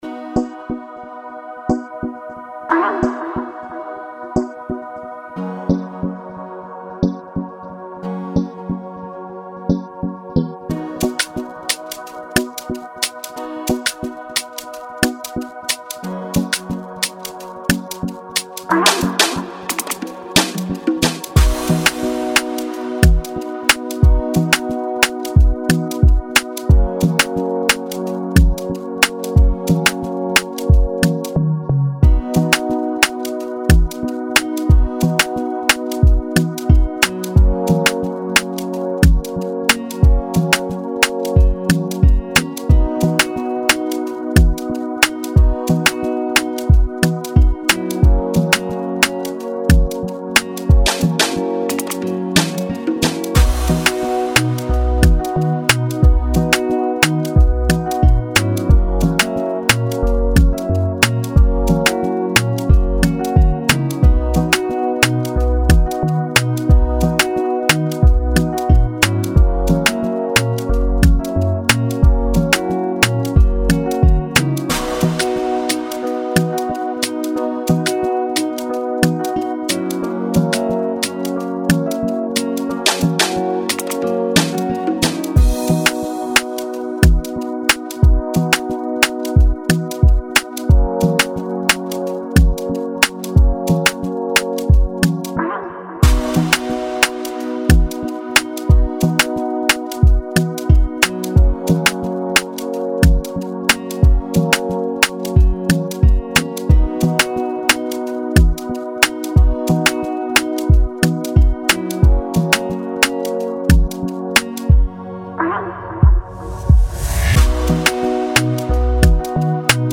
a dynamic instrumental
Embrace the Afro-pop essence